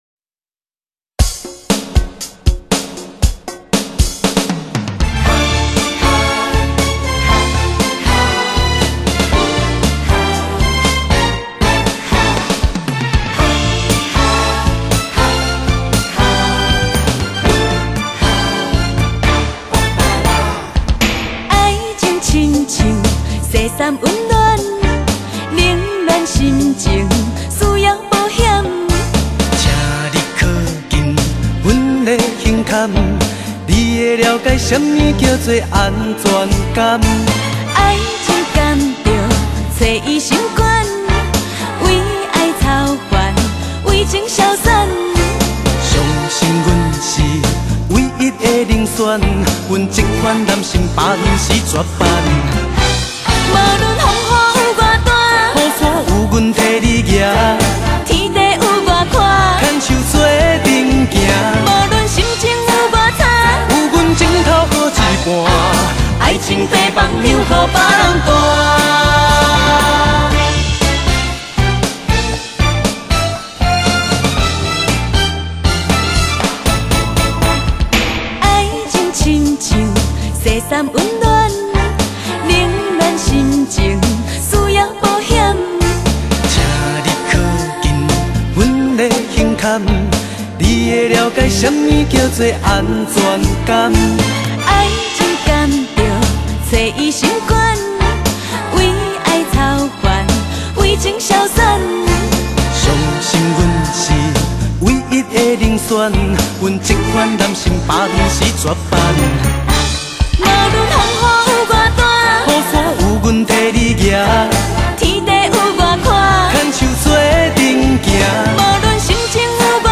【华语流行】
精选合唱
俊男美女2009再度甜蜜對唱